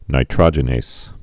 (nī-trŏjə-nās, -nāz, nītrə-jə-)